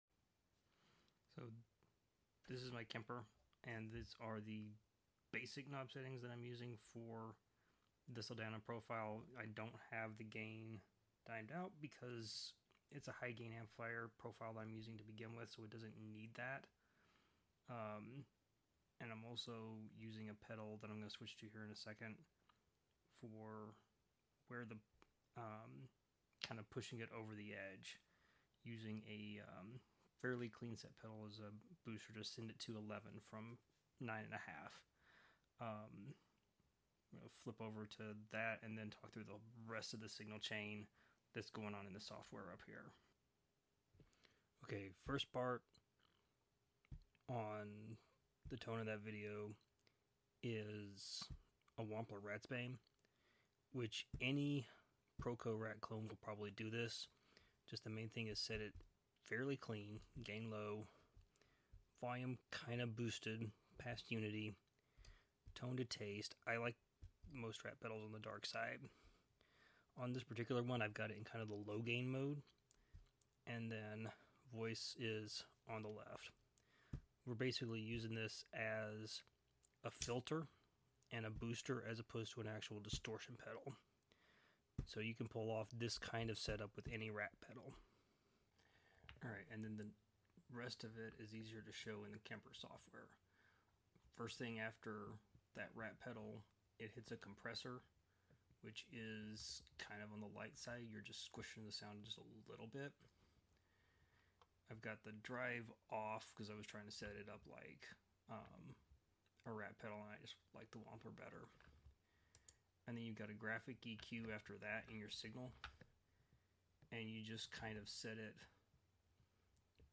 Tone walkthrough of my high gain sound I’ve been using with my Kemper.